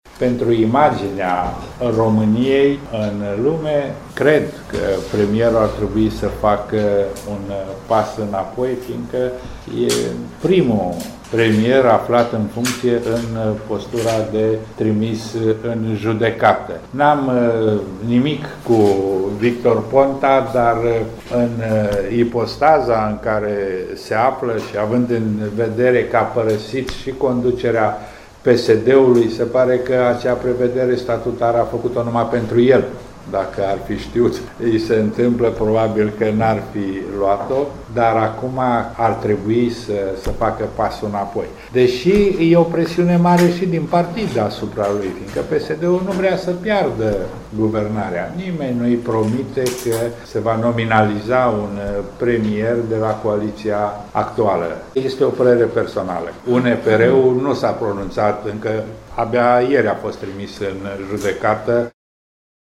Senatorul UNPR, Ion Simeon Purec, secretarul Biroului Permanent al Senatului României, a declarat astăzi la Reşiţa, că imaginea ţării este prejudiciată de premierul Victor Ponta, aflat într-o situaţie delicată. În opinia sa, Ponta ar trebui să demisioneze.